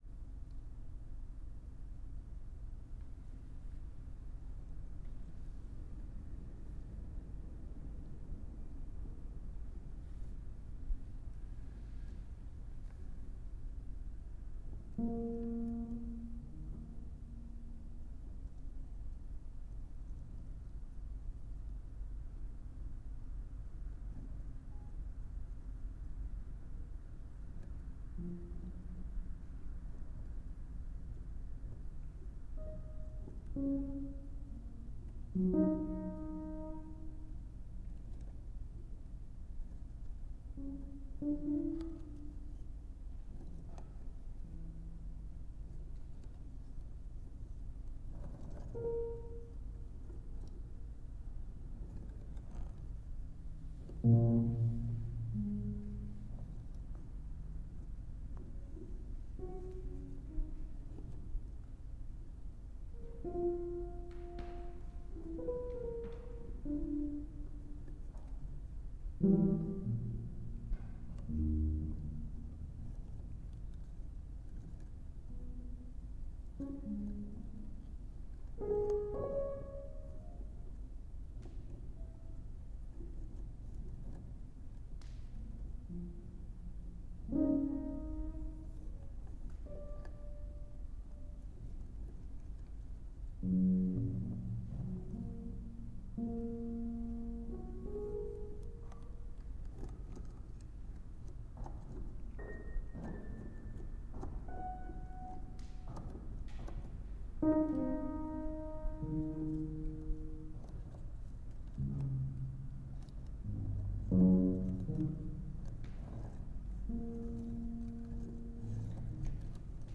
piano
tenor saxophone
alto saxophone
drums
guitar
double bass
trumpet